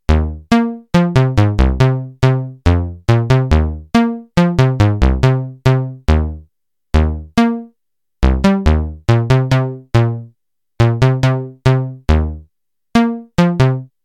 The program I implemented essentially “evolves” a simple musical grammar based on the user’s tastes and renders the sounds using a synthesized bass patch.
The first 3 examples sound similar because they are based on the same grammar derived during one run.